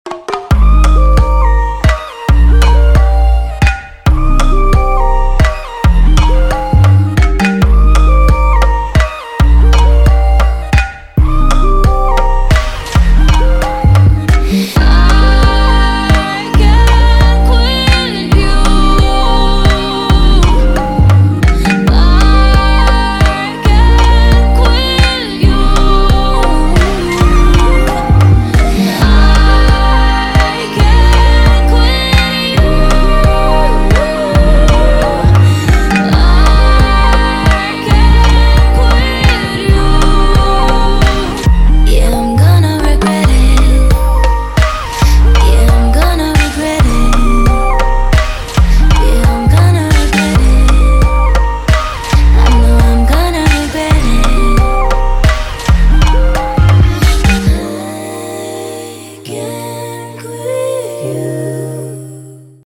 • Качество: 320, Stereo
Electronic
Bass
vocal